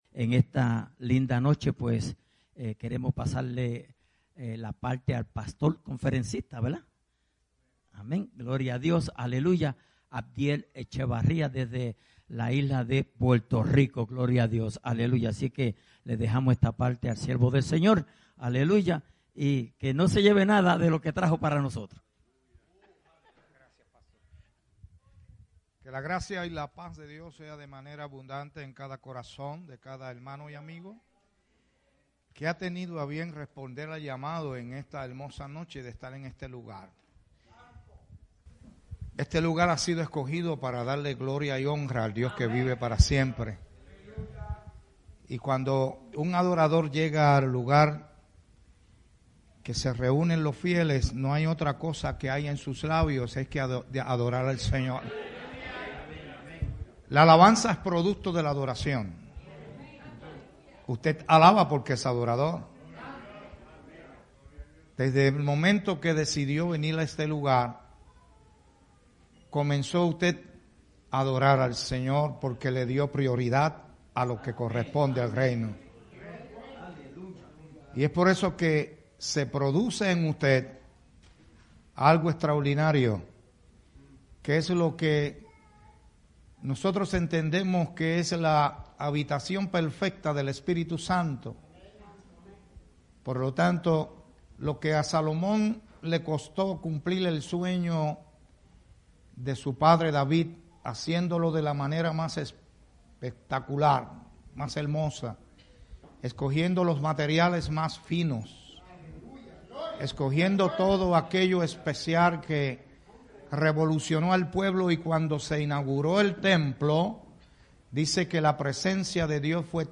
Grabado en Souderton